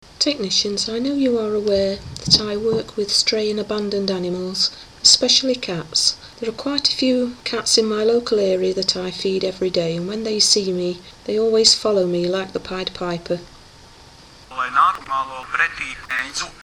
In this recording I am talking to our Technicians about my work with these animals, I must admit, I do find their response a little embarrassing!